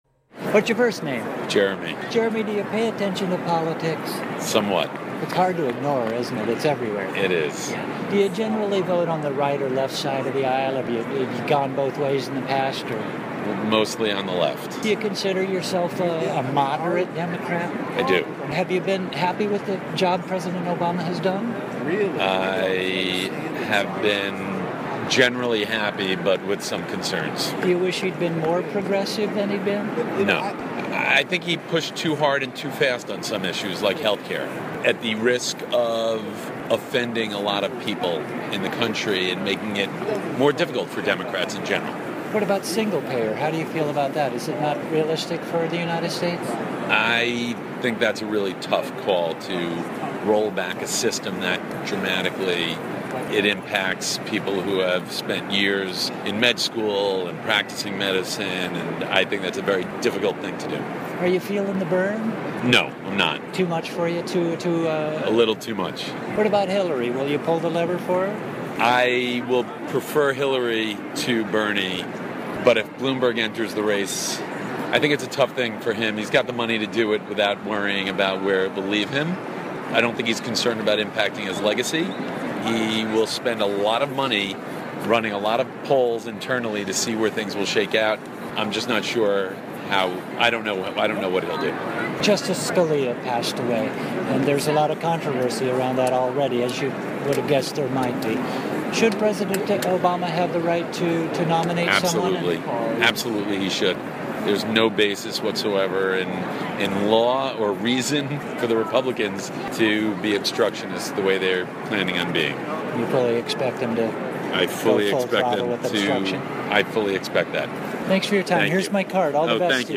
Set: Two Young Artists from the New School